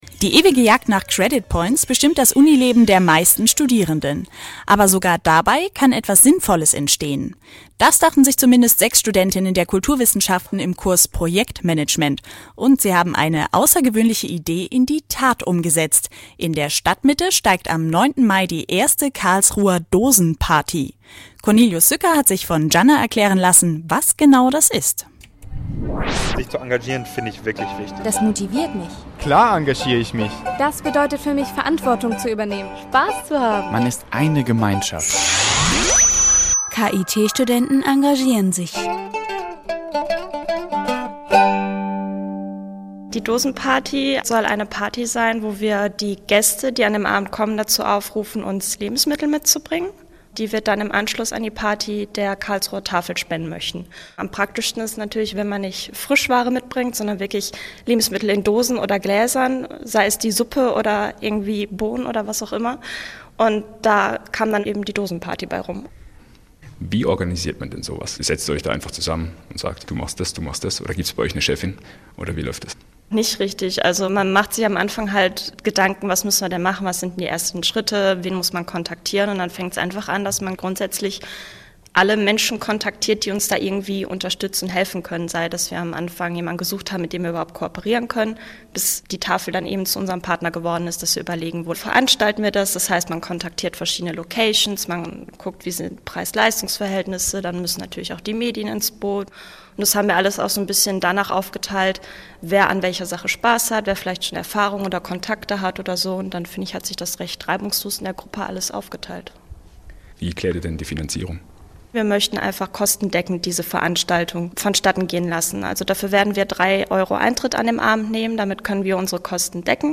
Karlsruher Dosenparty - Beitrag bei Radio KIT am 26.04.2012